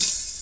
Tuned hi hats Free sound effects and audio clips
• Loud Open Hat Sound B Key 04.wav
Royality free open hat sound tuned to the B note. Loudest frequency: 10126Hz
loud-open-hat-sound-b-key-04-Sk6.wav